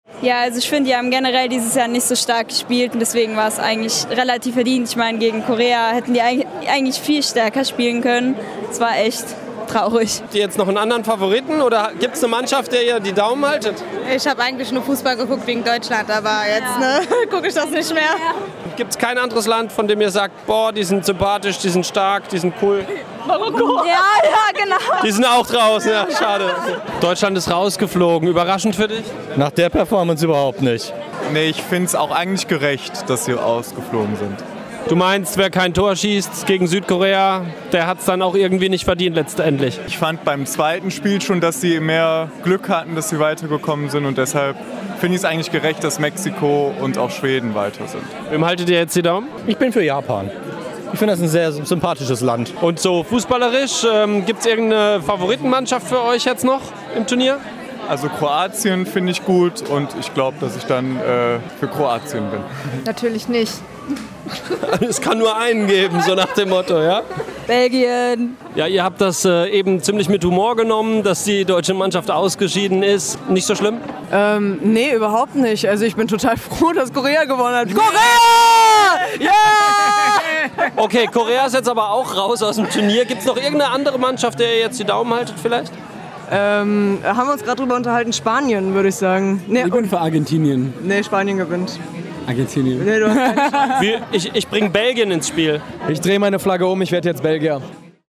Am Mittwochnachmittag aus dem laufenden Turnier verabschiedet hat sich der noch amtierende Weltmeister Deutschland. Zurecht, fanden auch diese deutschen Fans beim Public Viewing in Aachen, die es recht locker und sportlich nahmen: